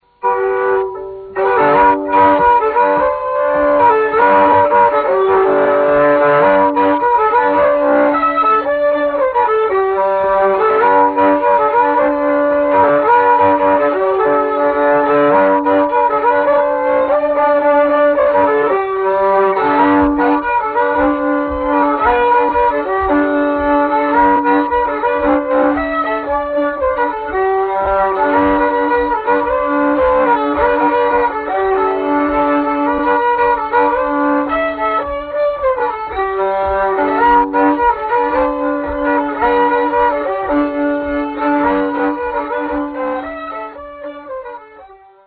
The Legendary West Virginia Fiddler from 1947 Field Recordings
It is as though somebody were calling to us from beyond the grave.
It is dim, distant and distorted and nobody speaks.  There are none of the background noises, like ticking clocks and barking dogs, which a chap expects to hear on field recordings. (sound clip - Let's Hunt the Horses)
"Sounds like those digital blighters have been a bit heavy handed with the audio restoration," I snorted.